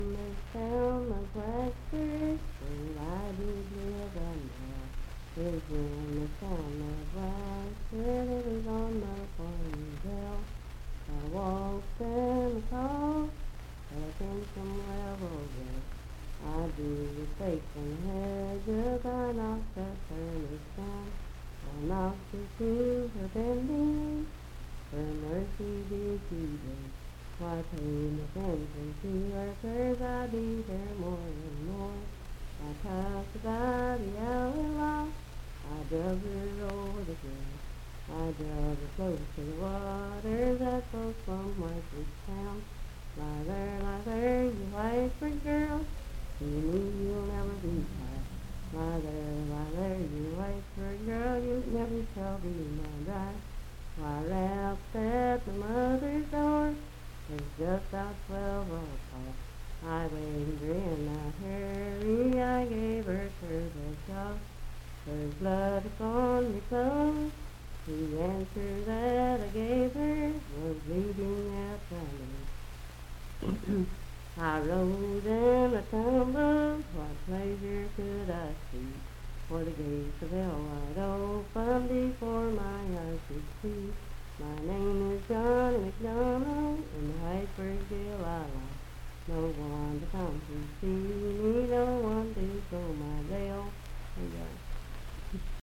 Unaccompanied vocal music
Verse-refrain 4d(4).
Voice (sung)